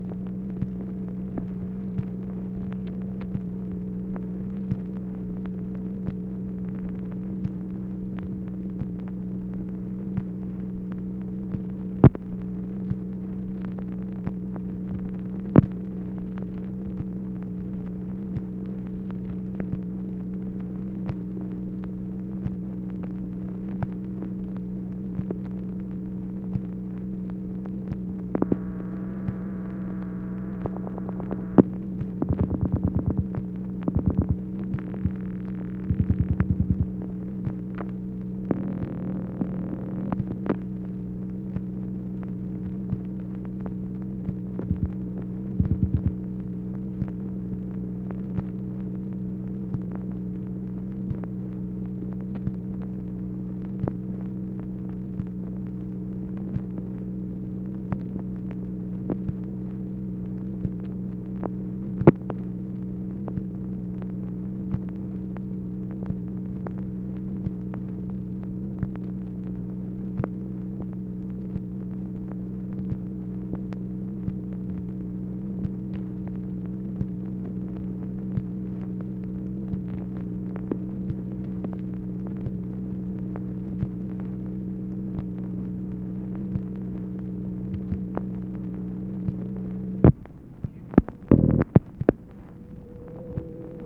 MACHINE NOISE, January 29, 1964
Secret White House Tapes | Lyndon B. Johnson Presidency